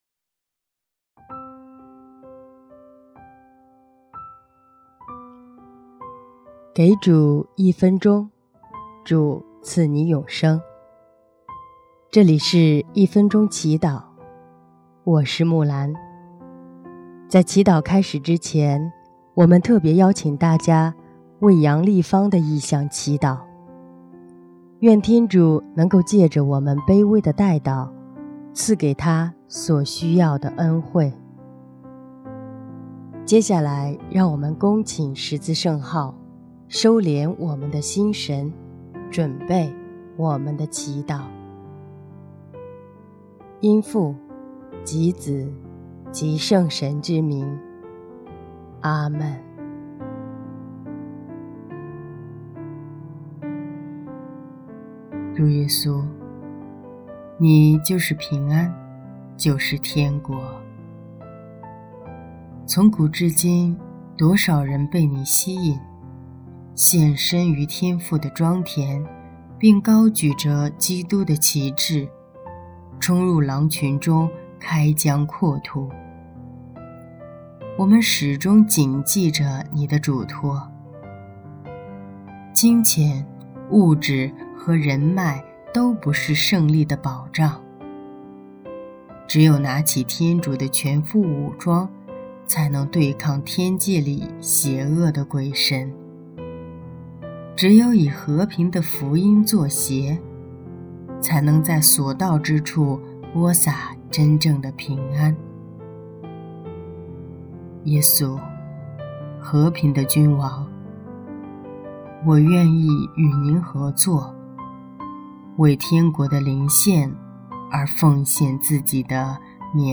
【一分钟祈祷】|耶稣，和平的君王，我愿意与祢合作（10月18日）